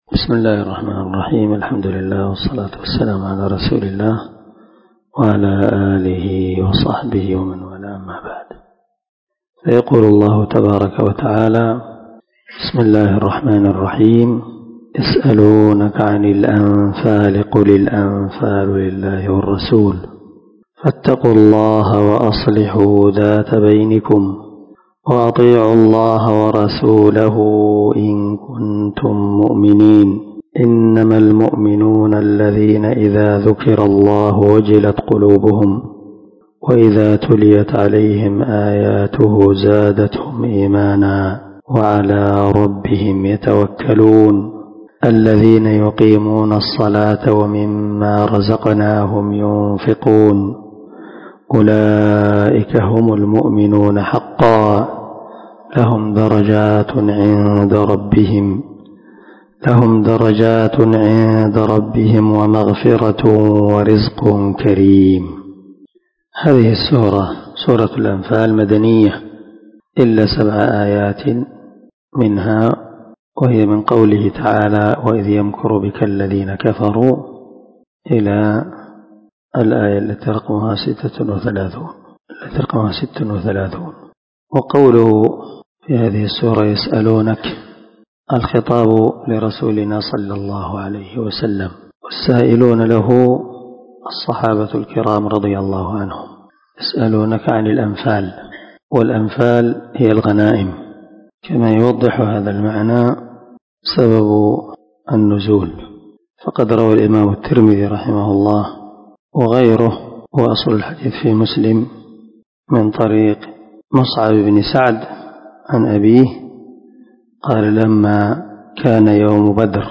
504الدرس 1 تفسير آية ( 1 - 4 ) من سورة الأنفال من تفسير القران الكريم مع قراءة لتفسير السعدي
دار الحديث- المَحاوِلة- الصبيحة.